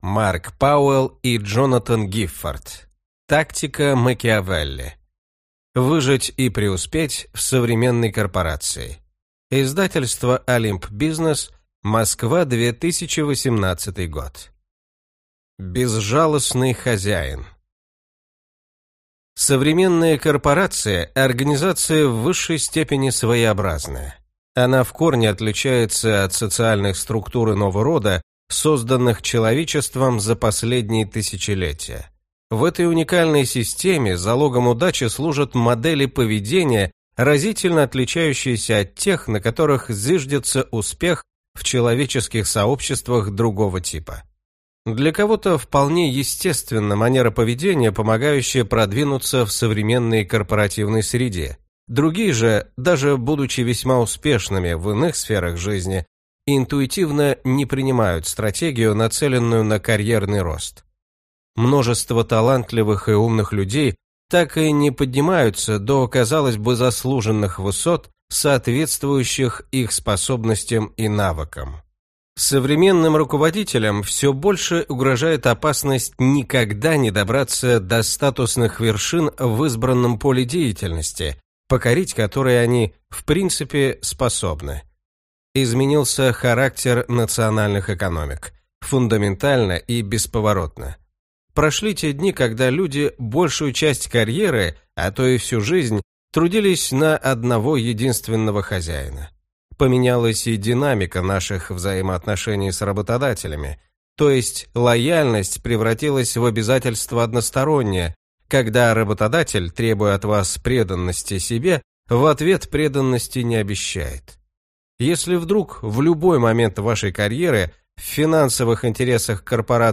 Аудиокнига Тактика Макиавелли. Выжить и преуспеть в современной корпорации | Библиотека аудиокниг